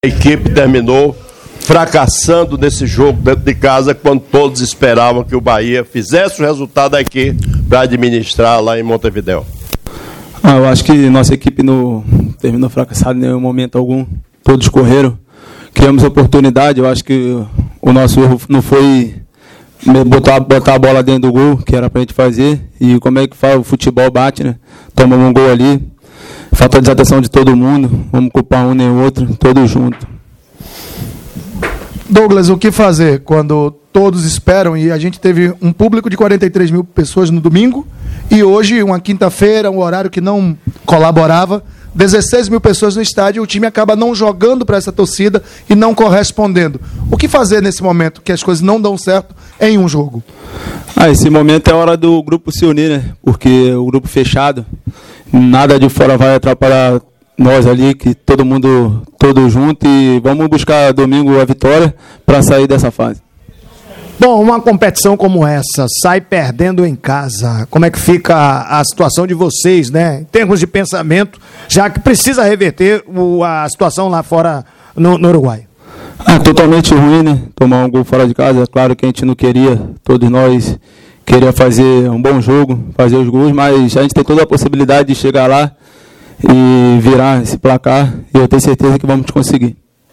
Após o revés, o técnico Enderson Moreira avaliou a atuação da equipe e falou sobre a estratégia que tentou executar no início da partida. O comandante Tricolor também admitiu a necessidade de fazer ajustes na equipe, mas se manteve confiante para reverter a vantagem uruguaia e buscar a classificação no dia 21 de fevereiro, em Montevidéu.